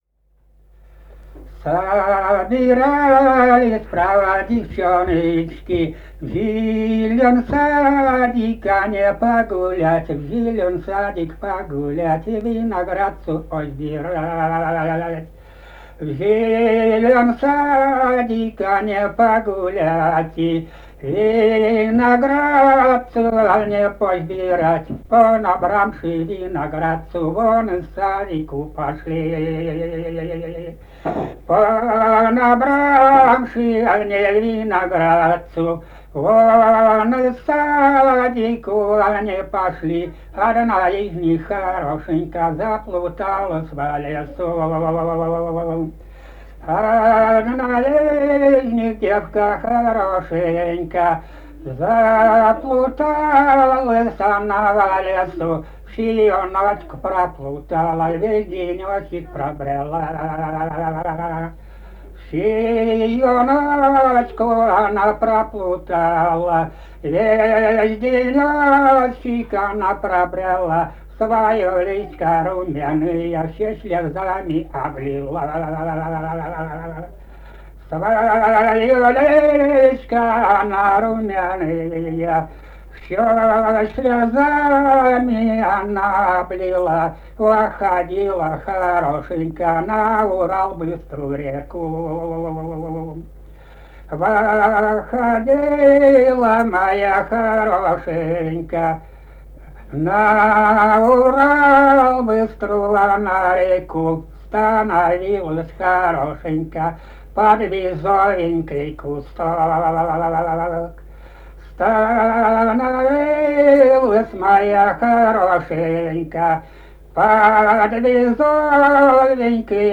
полевые материалы
Казахстан, г. Уральск, 1972 г. И1312-07